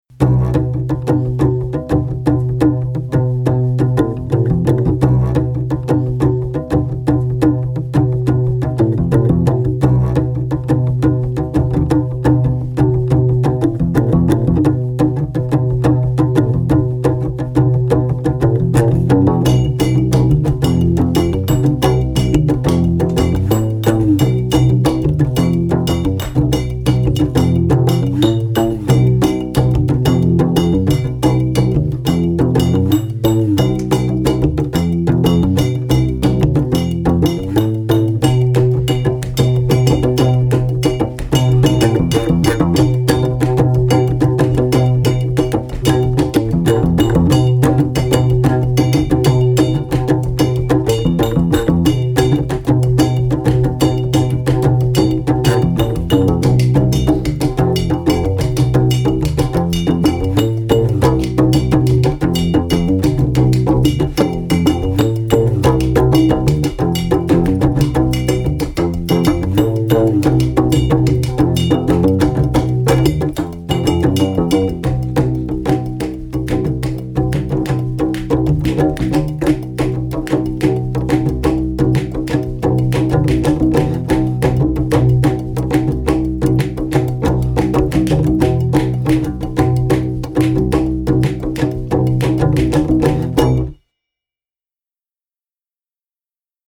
Album outtake
a whimsical little ditty
the Moroccan sintir and a metal travel mug